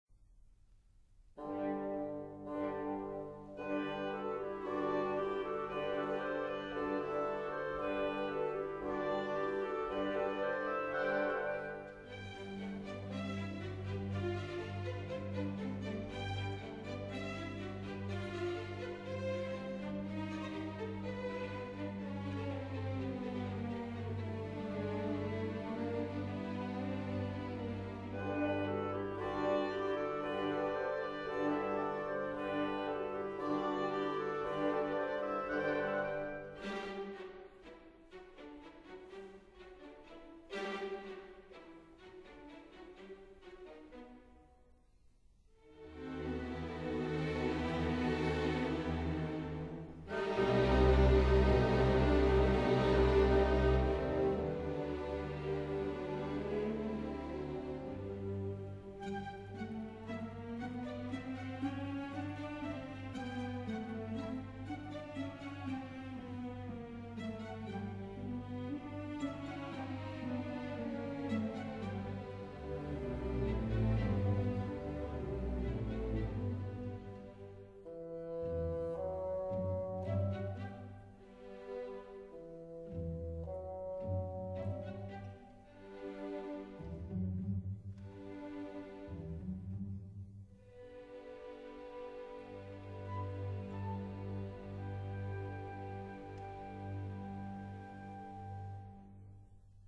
Sorry, 论坛限制只许上传10兆一下，而且现在激动的网速巨慢，所以只能牺牲品质，为大家介绍一下音乐了。